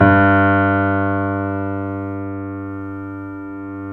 Index of /90_sSampleCDs/Roland - Rhythm Section/KEY_YC7 Piano pp/KEY_pp YC7 Mono